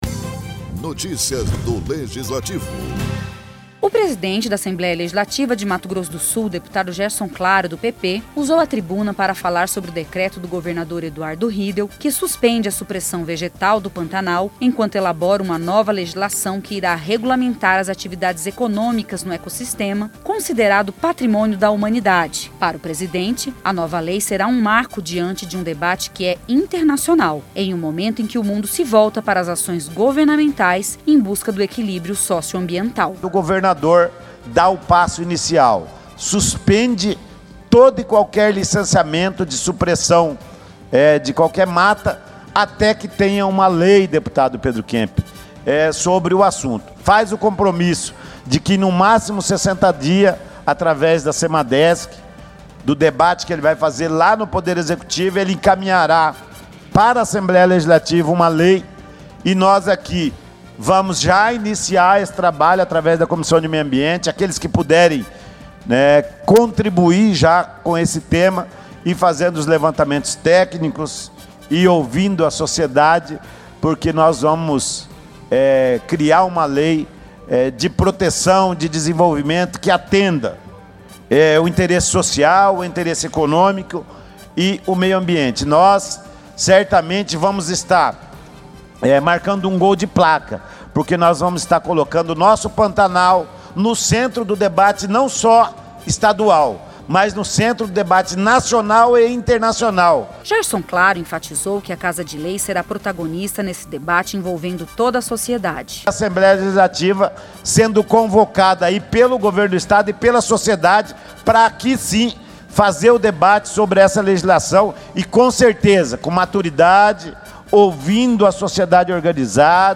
Na tribuna ALEMS, durante a sessão ordinária, o presidente da Casa de Leis, deputado Gerson Claro (PP), falou sobre a participação do Estado de Mato Grosso do Sul no lançamento do Novo Programa de Aceleração do Crescimento (PAC), do Governo Federal.